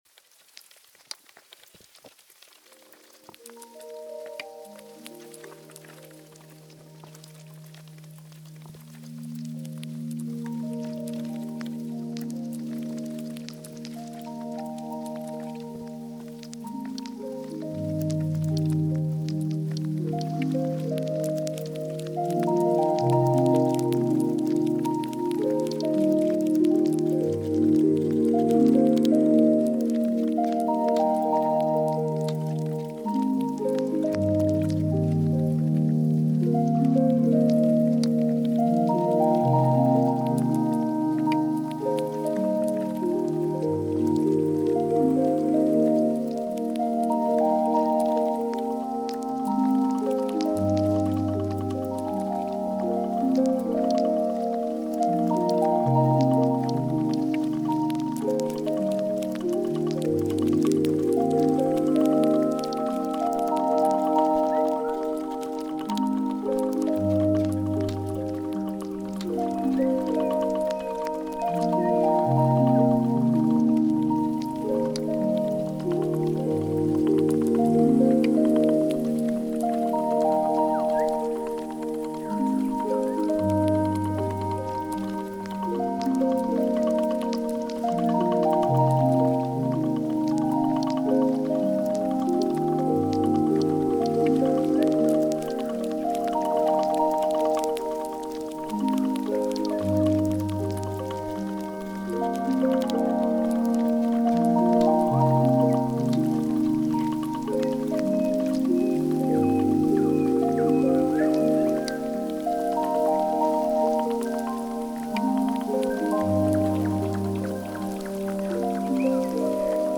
ambient music